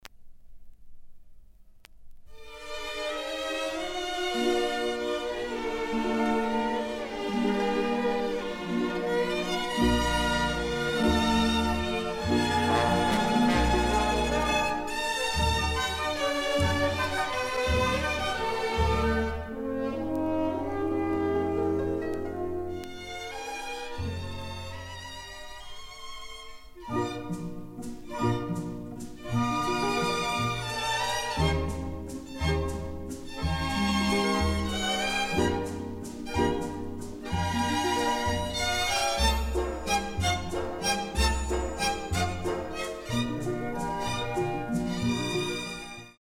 Standard Schallplattenwäsche
Lediglich noch kleine Kratzer sind zu hören. Die Platte ist in normalem Zustand.
klassik_gewaschen.mp3